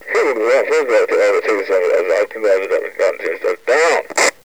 police radio chatter 01.wav